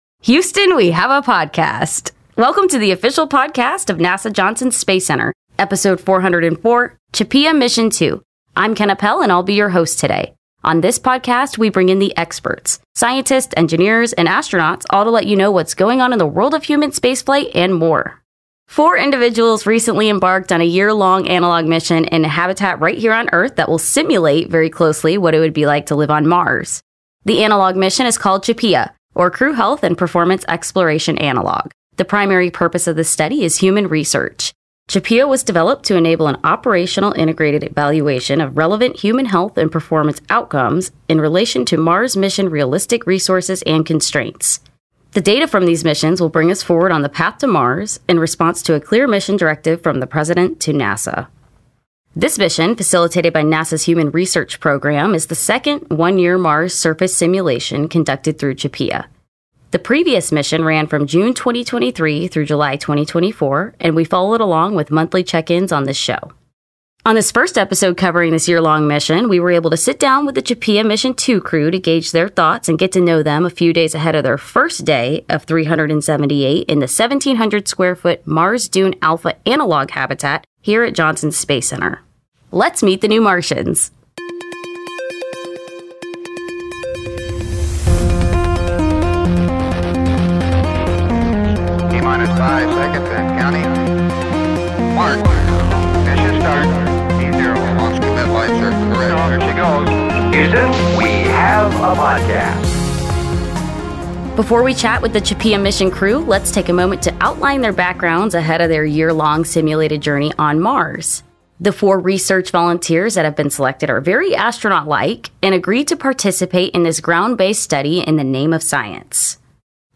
Listen to in-depth conversations with the astronauts, scientists and engineers who make it possible.
The CHAPEA Mission 2 crew discusses their backgrounds and preparations for their upcoming yearlong analog Mars mission inside the Mars Dune Alpha habitat at NASA’s Johnson Space Center.